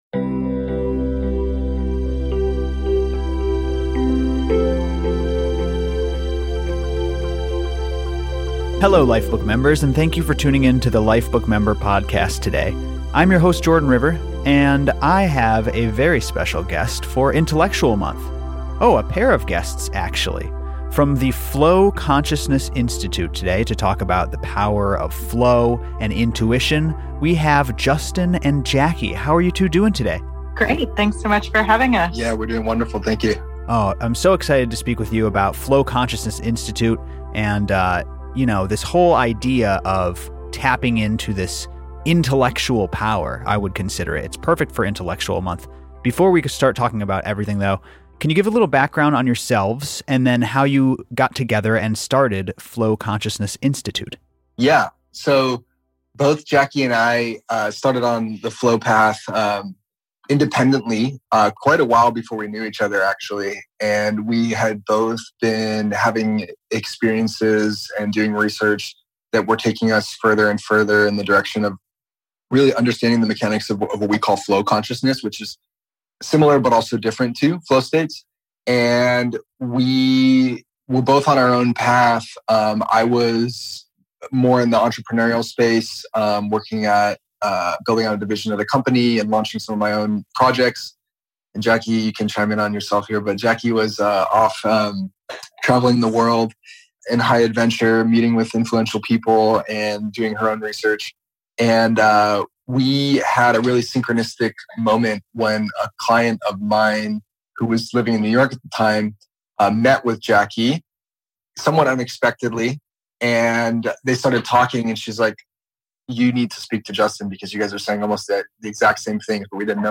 Expert Interview